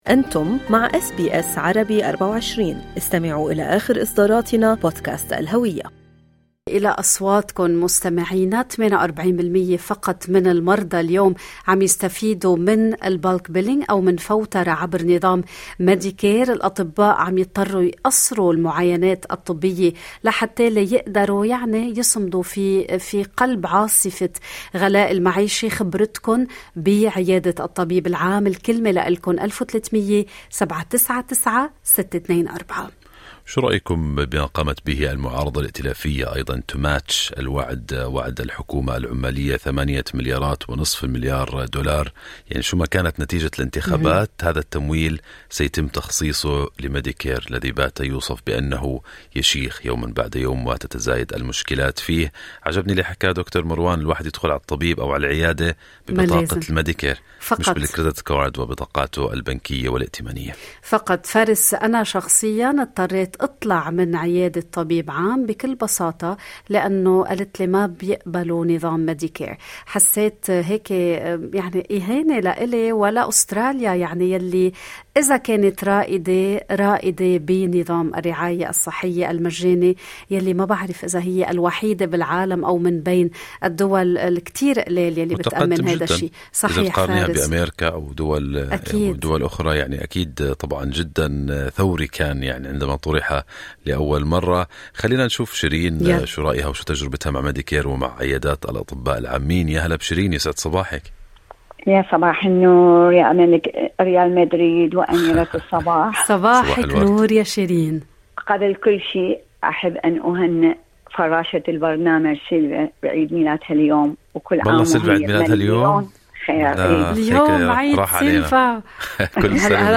فتحنا الخطوط للمستمعين لمشاركة تجاربهم الشخصية مع النظام الصحي، واليكم شهادات التي تسلط الضوء على الجوانب الإيجابية والتحديات التي لا يزال يعاني منها كثيرون في النظام الصحي الأسترالي.